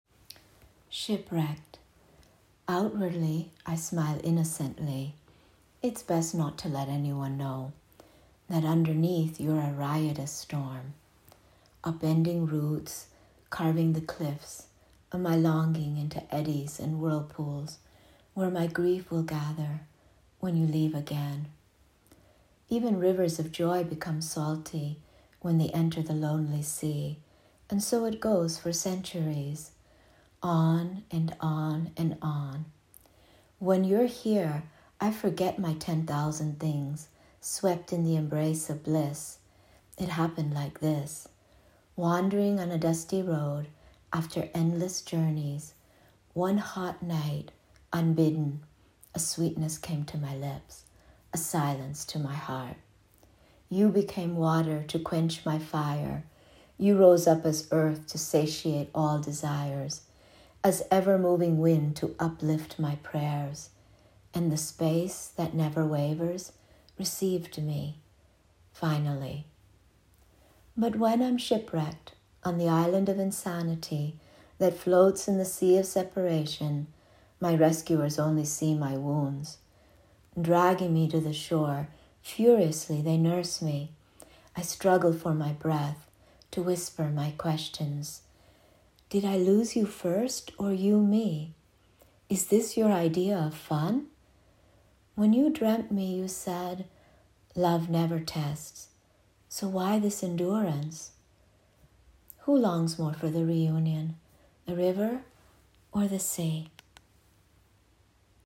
As poems are meant to be read out loud